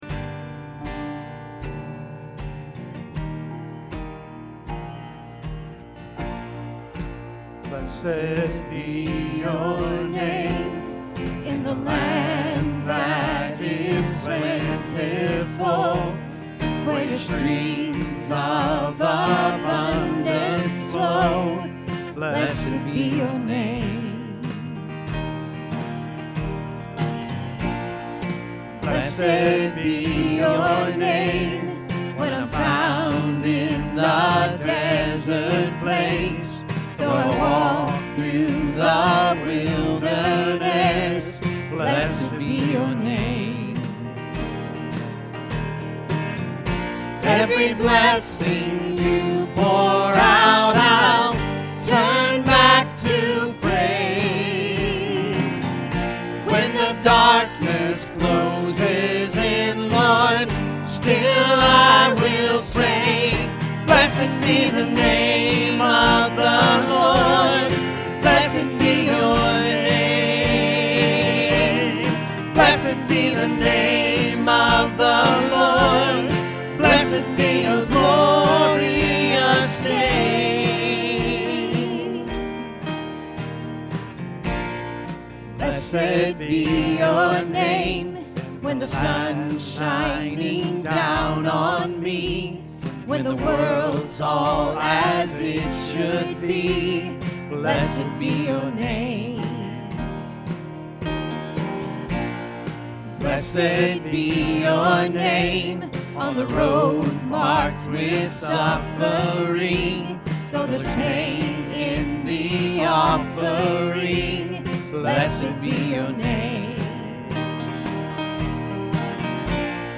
Sunday Sermon October 3, 2021